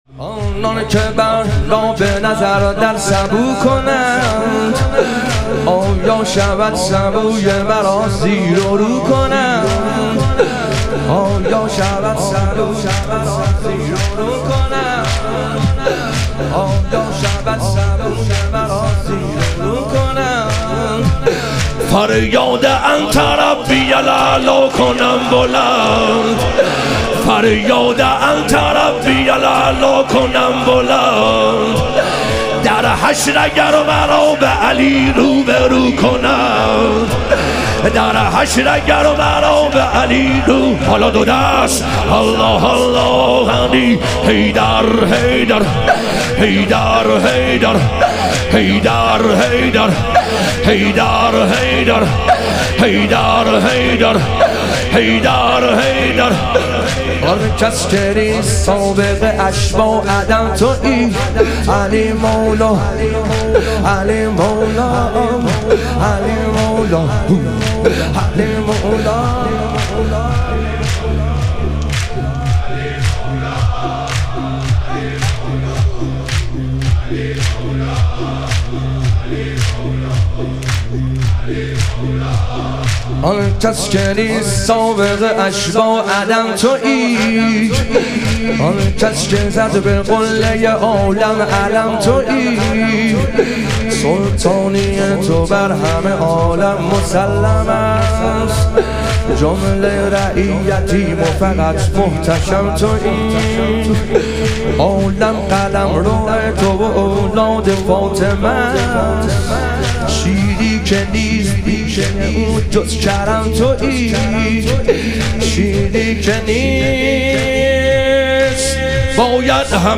شهادت حضرت زینب کبری علیها سلام - تک